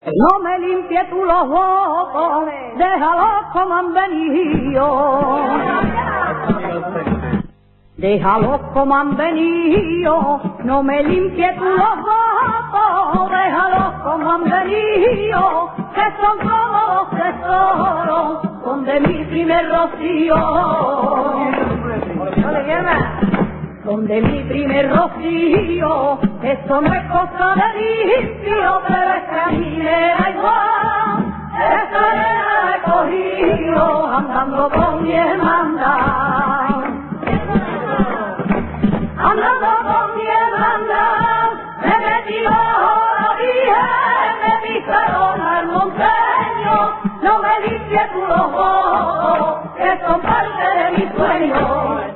SEVILLANAS ROCIERAS